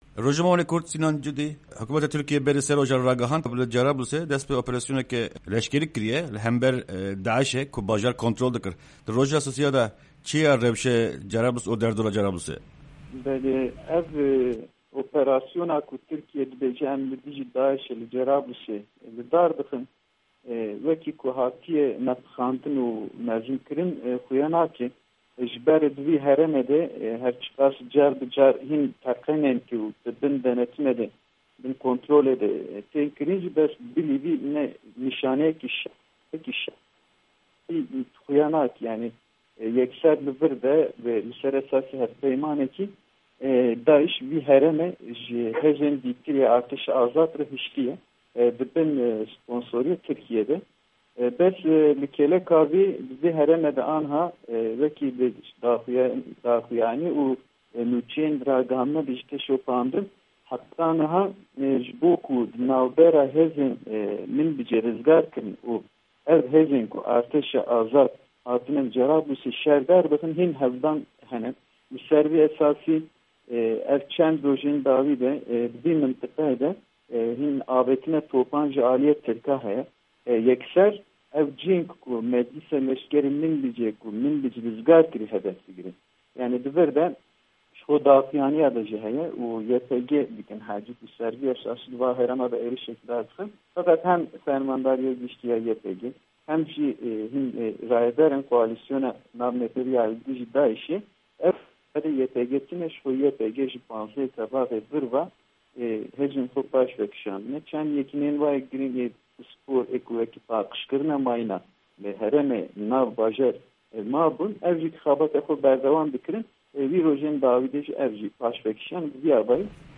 Di hevpeyvîna Dengê Amerîka de